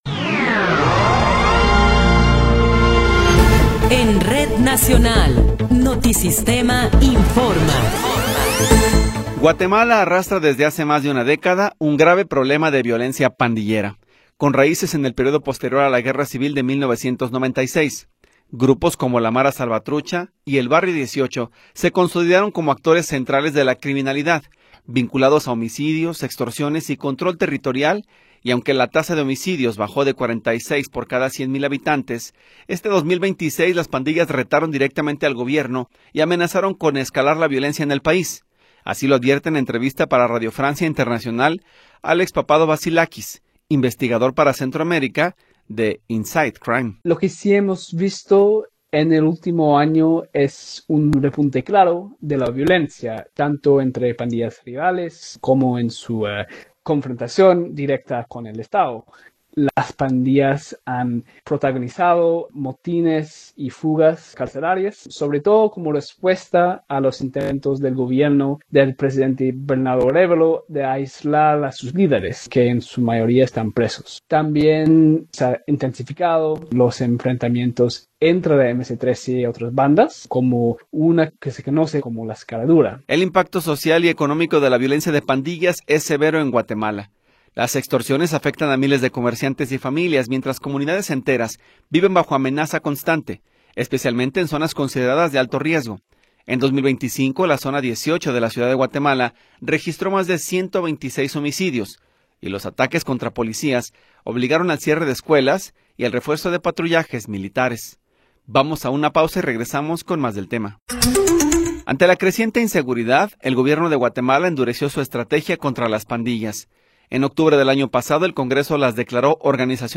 Noticiero 13 hrs. – 1 de Febrero de 2026
Resumen informativo Notisistema, la mejor y más completa información cada hora en la hora.